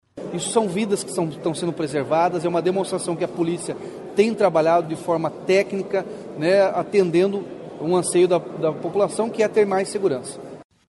SONORA-RATINHO-JUNIOR.mp3